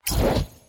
На этой странице собраны звуки, ассоциирующиеся с черной магией: загадочные шёпоты, ритуальные напевы, эхо древних заклинаний.
Волшебная палочка взмахнула